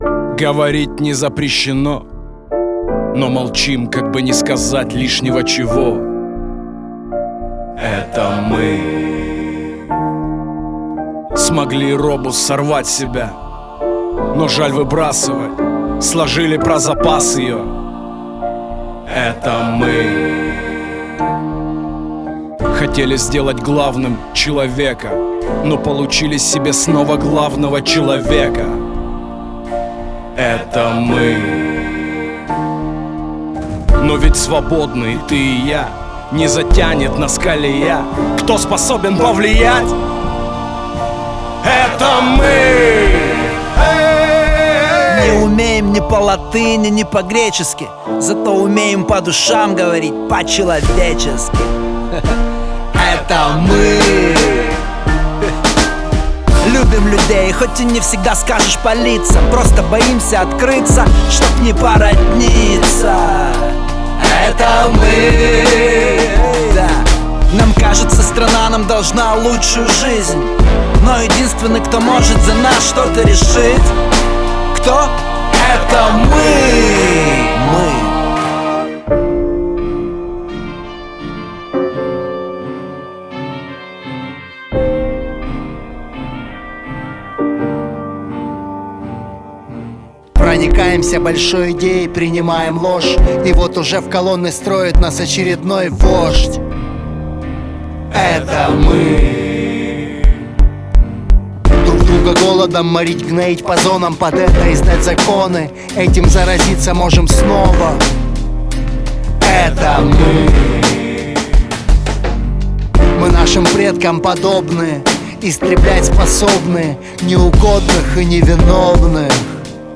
Жанр: Hip-Hop, Rap